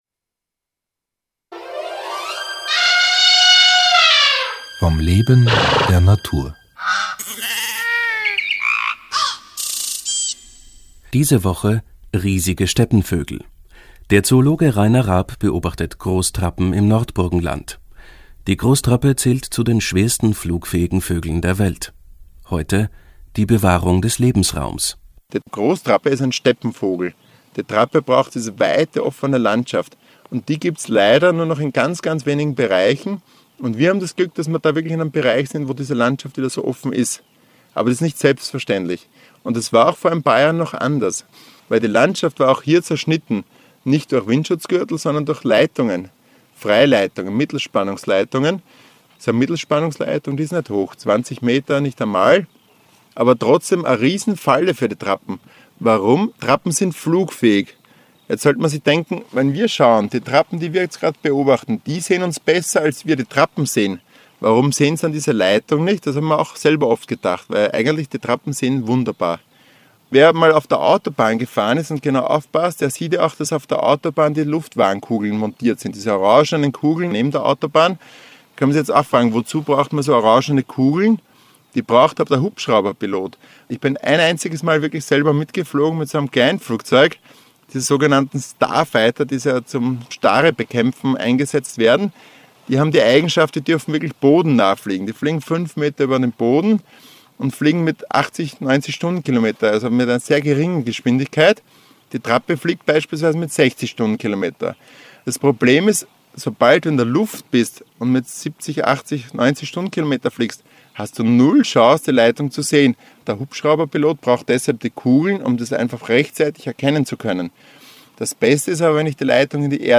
Interviews
broadcasted on the radio